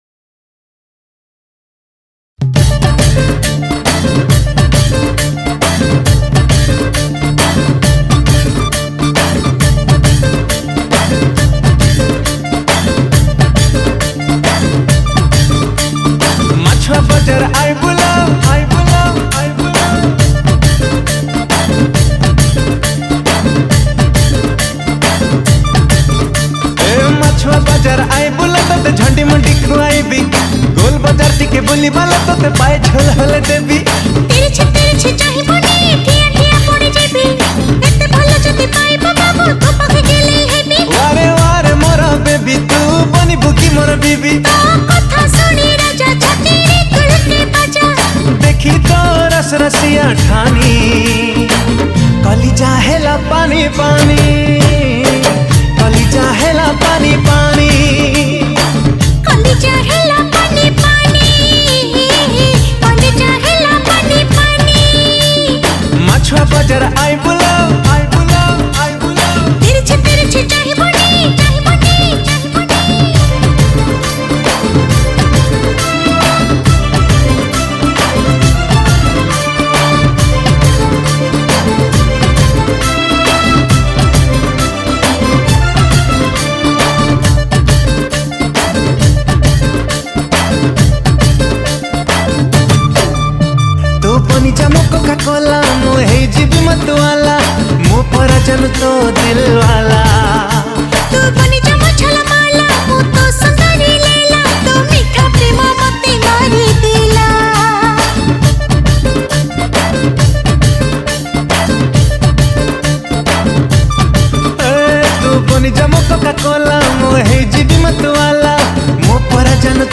Song Type :Dance